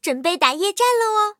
M22蝉夜战语音.OGG